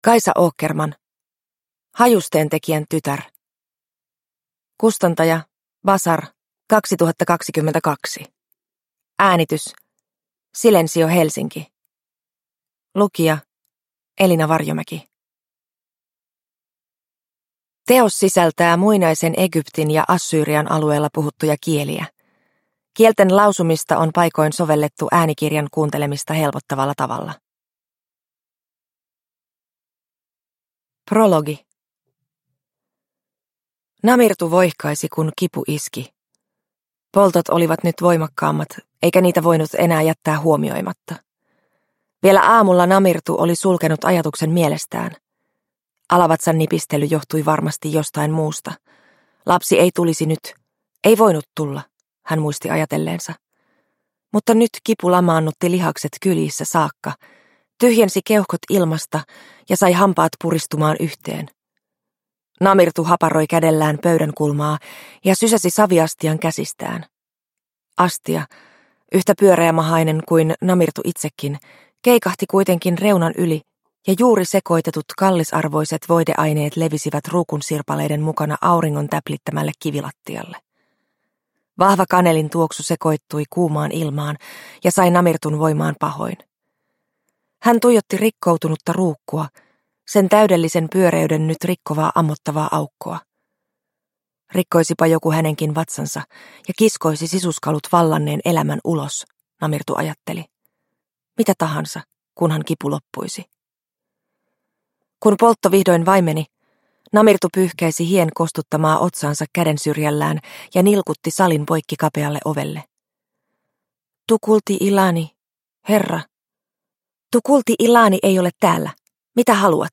Hajusteentekijän tytär – Ljudbok – Laddas ner